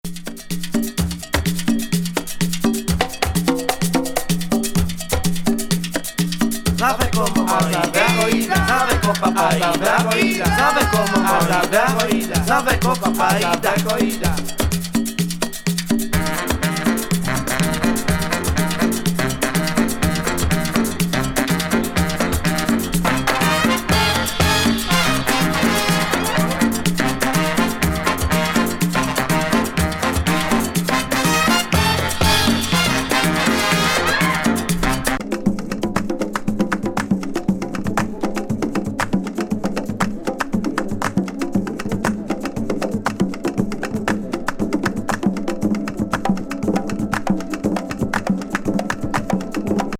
サルサ曲集。